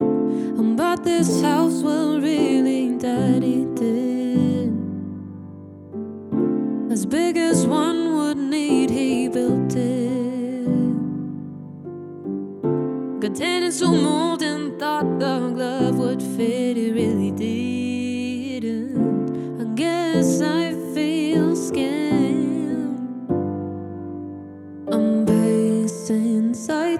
• Pop
• Singer/songwriter
Vokal